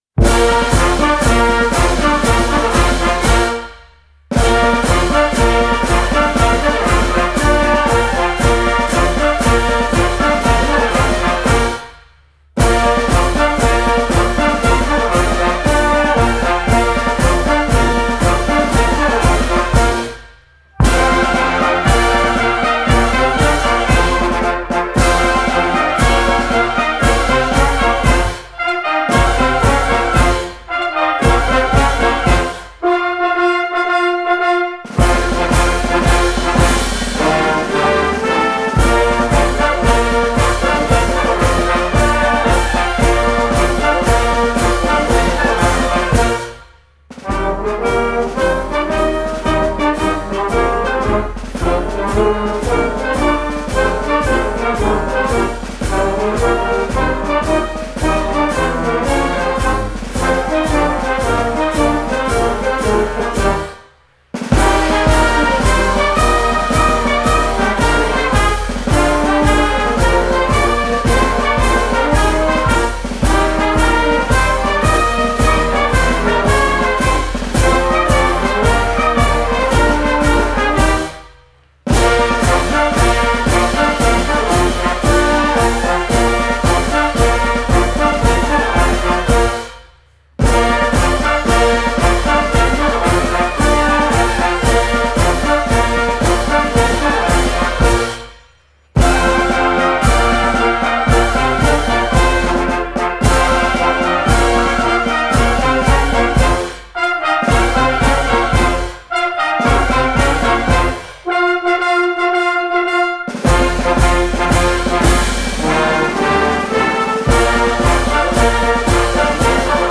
Tras un intenso trabajo de preservación cultural, la emblemática Diana de Santiago ha sido grabada en estudio para asegurar que su melodía perdure intacta en el tiempo.
Esta grabación es una interpretación fiel a la partitura original de D. Justo Jiménez Montes.
La ejecución ha corrido a cargo de la A.M. Justo Jiménez, quienes con su habitual maestría y oficio, han puesto alma a cada nota para que esta pieza suene con el esplendor que merece.